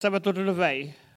Collectif patois et dariolage